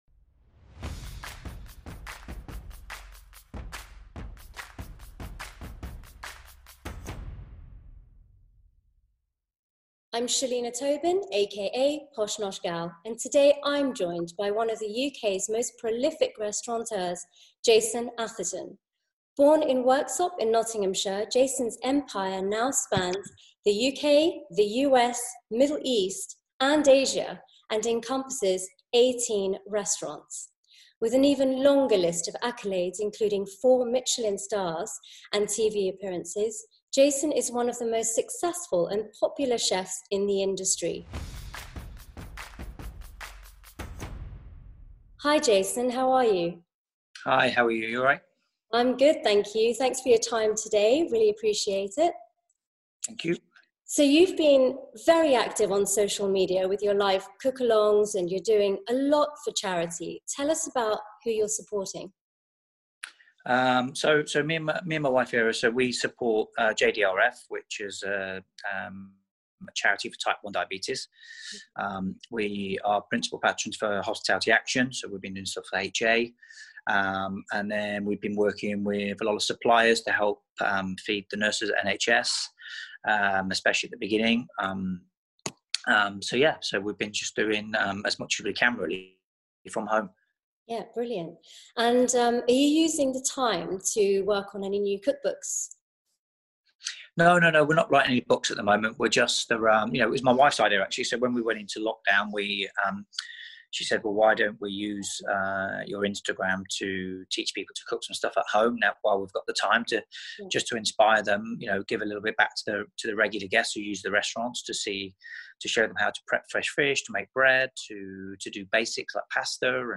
interviews the celebrated chef and restauranteur Jason Atherton about life during lockdown, and the future of the industry